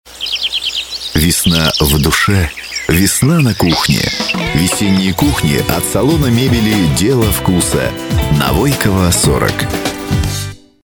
Рекламные радио ролики записаны в формате mp3 (64 Kbps/FM Radio Quality Audio).